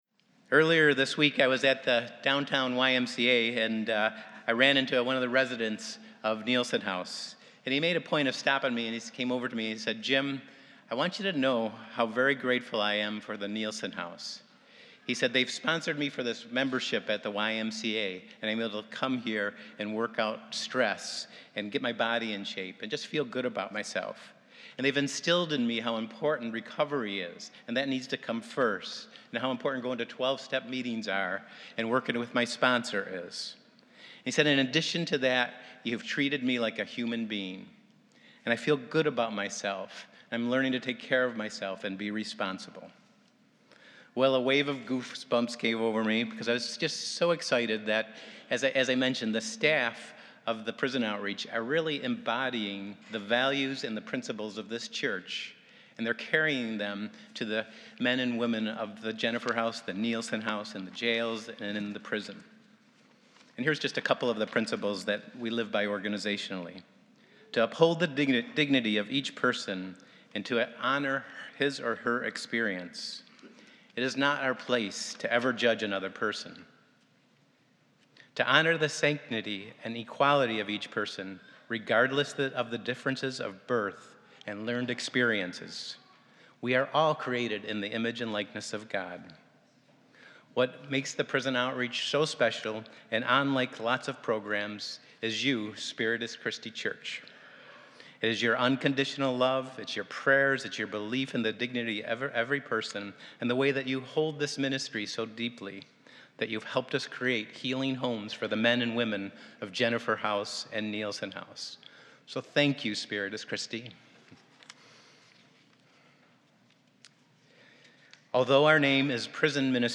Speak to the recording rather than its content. This is a Spiritus Christi mass in Rochester, NY. We are celebrating the 38th year of our Spiritus Christi Prison Outreach Ministry.